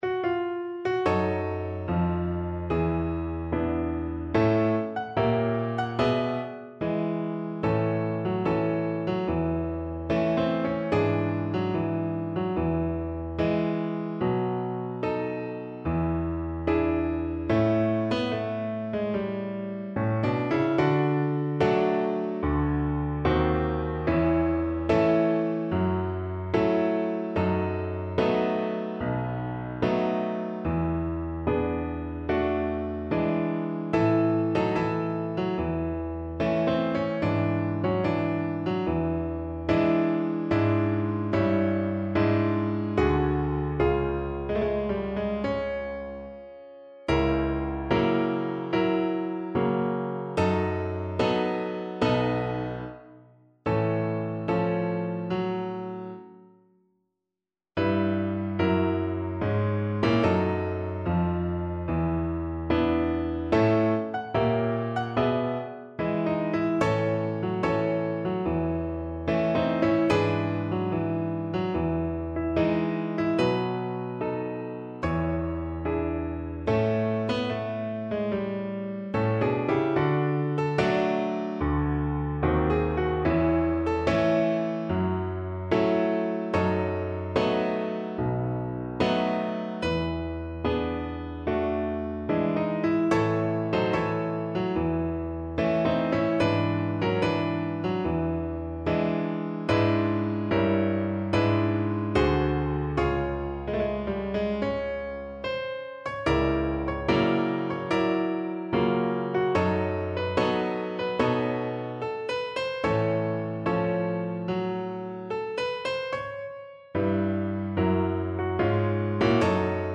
Moderate Swing = c.100
4/4 (View more 4/4 Music)
Jazz (View more Jazz Violin Music)